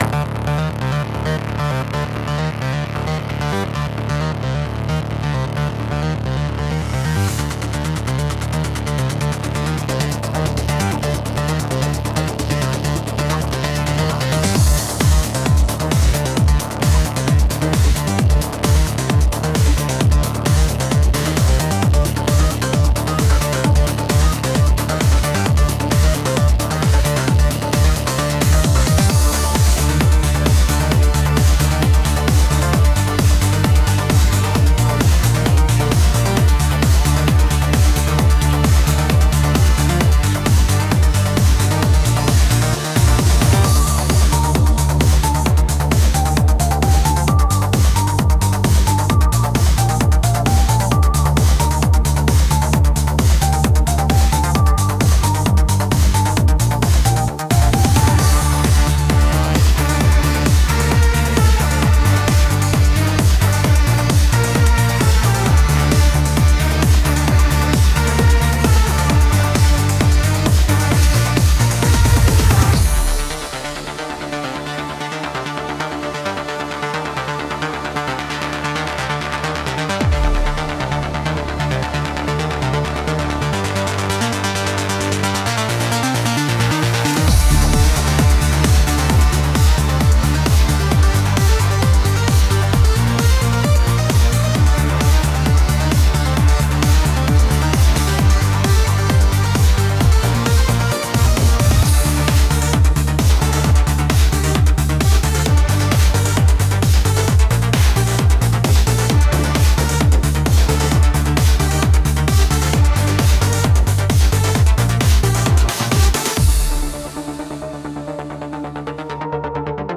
Пример музыки созданной ИИ по моему промпту.
Промпт: Dark Techno / Industrial. Theme music for a female Cybersecurity Expert. High energy, strong rhythm, complex, cold synths, and a sense of powerful, relentless problem-solving. Make it sound like the digital frontier. No vocals or lyrical content. Instrumental only (перевод: Дарк-техно / Индастриал. Главная тема для девушки — эксперта по кибербезопасности. Высокая энергия, сильный ритм, сложные, холодные синтезаторы и ощущение мощного, неустанного решения проблем. Пусть это звучит как цифровая граница (передний край). Без вокала и лирического содержания. Только инструментальная музыка)